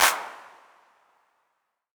• Clap One Shot C# Key 16.wav
Royality free clap sound clip - kick tuned to the C# note. Loudest frequency: 5185Hz
clap-one-shot-c-sharp-key-16-GwB.wav